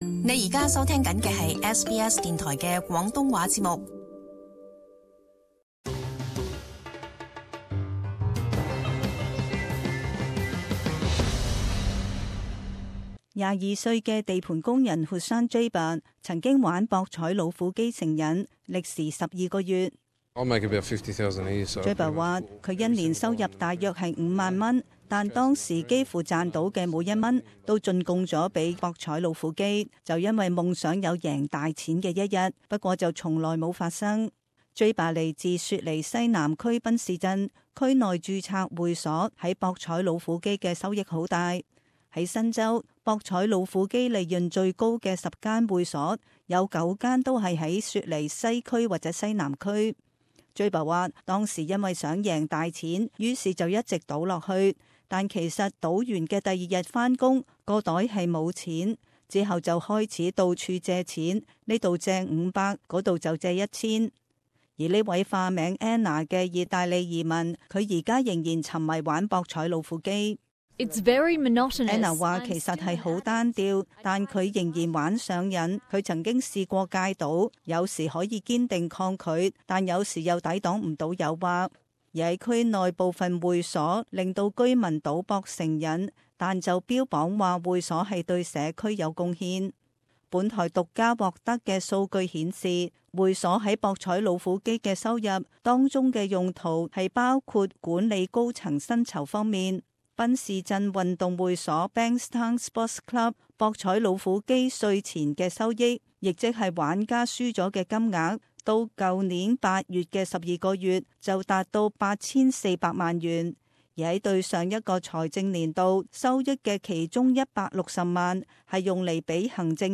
【時事報導】問題賭博